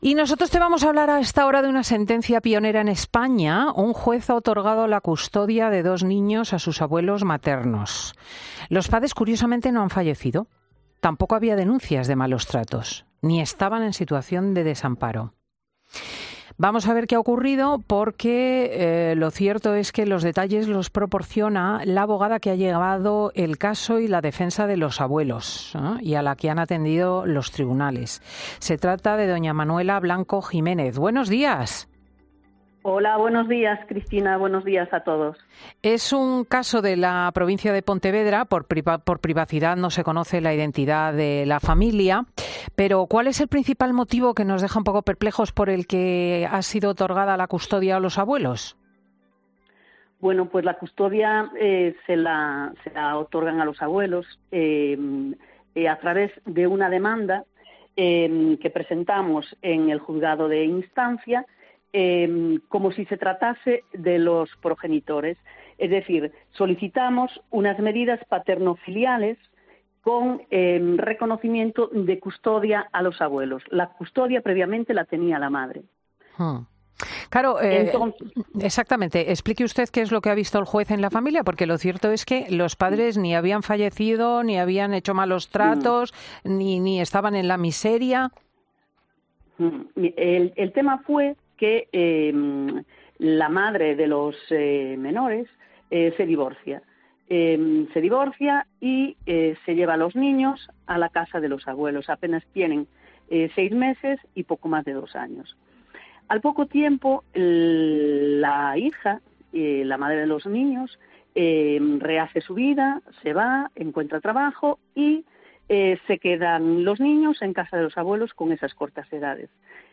Siempre, de la mano y la voz de Cristina López Schlichting, en cuyo dilatado currículum vitae se incluyen sus labores de articulista y reportera en los principales periódicos de España (ABC, El Mundo o La Razón o su papel de tertuliana de televisión.